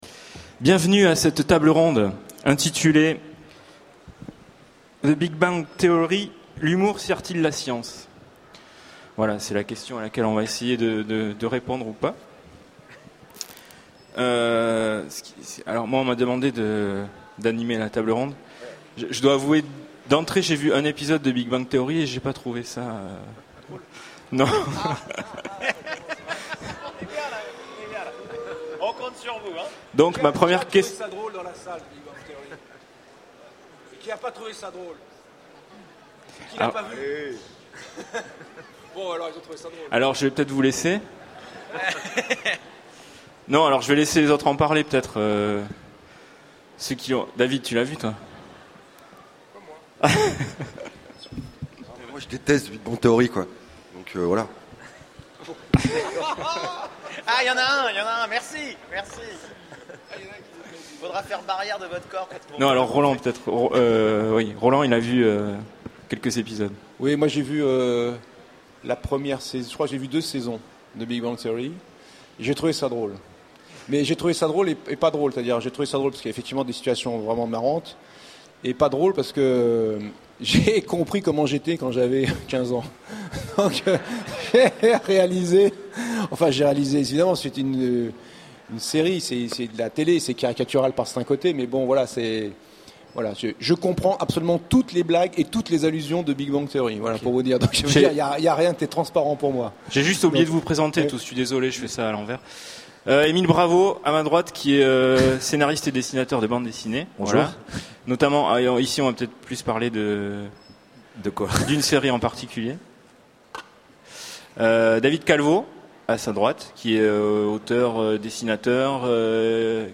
Utopiales 12 : Conférence The Big Bang Theory : l’humour sert-il la science ?